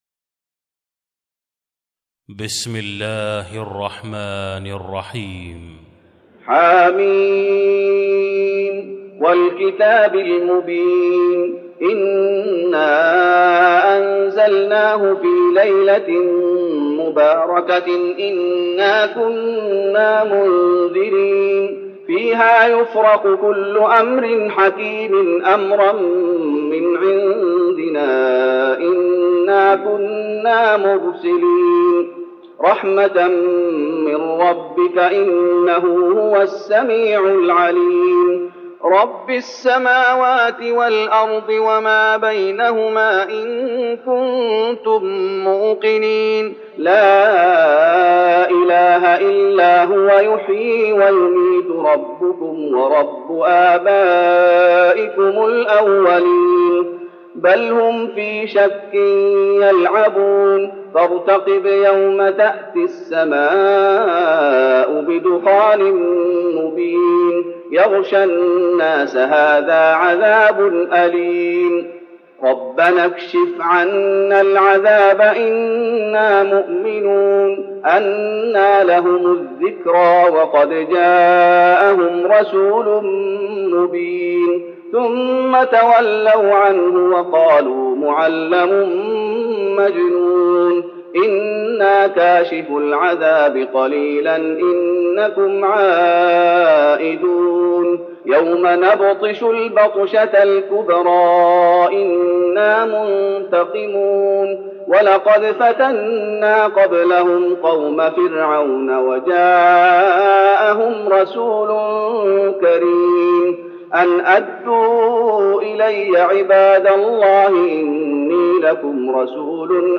تراويح رمضان 1413هـ من سورة الدخان Taraweeh Ramadan 1413H from Surah Ad-Dukhaan > تراويح الشيخ محمد أيوب بالنبوي 1413 🕌 > التراويح - تلاوات الحرمين